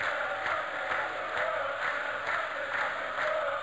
crowd.wav